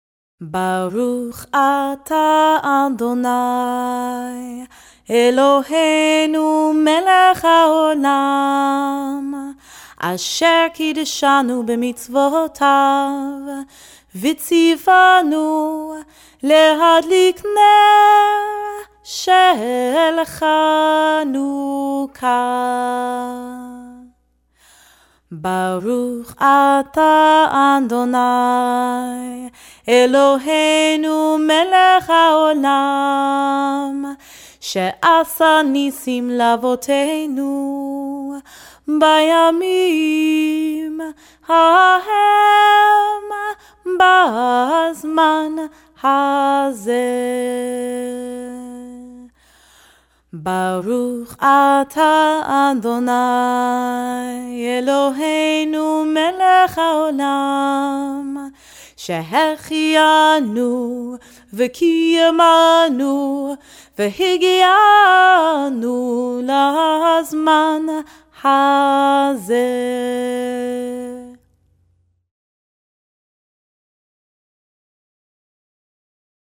17_chanukah_brachot.mp3